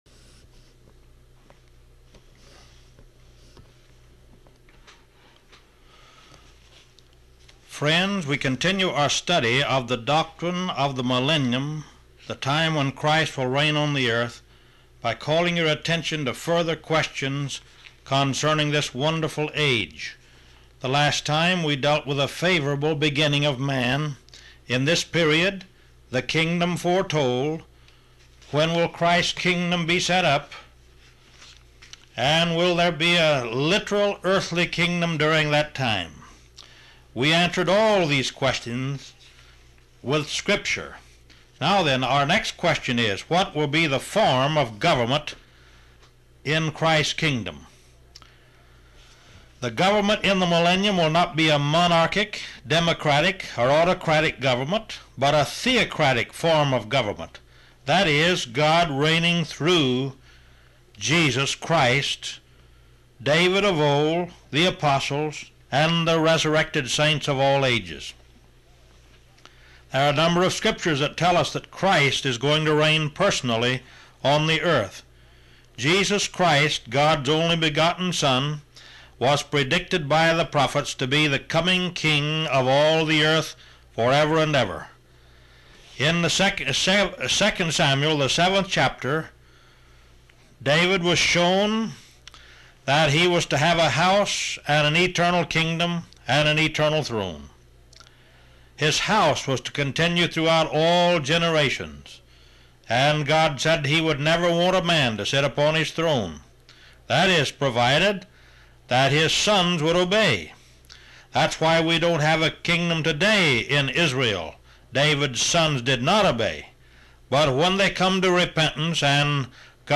taped beginning in 1948 out of an Atlanta Georgia studio.
radio ministry is captured in these live recordings.